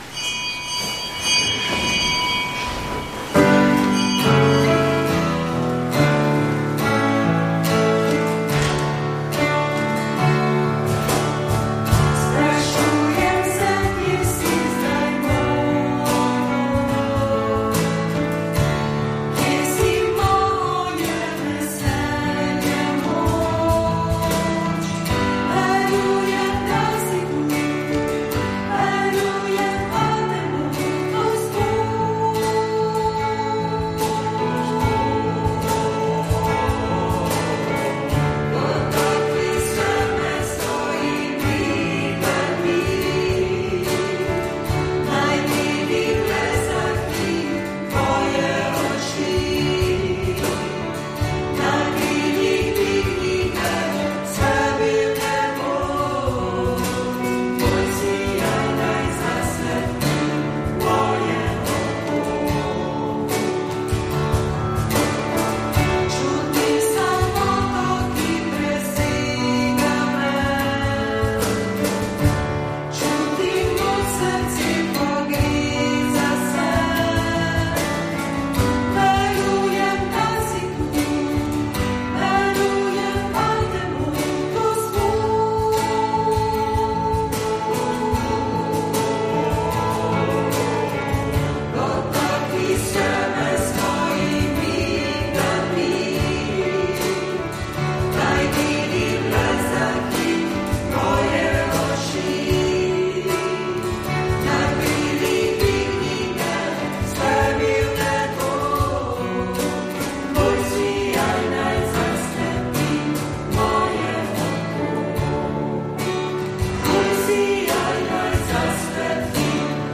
Sveta maša
Sv. maša iz župnije Nova Štifta pri Gornjem Gradu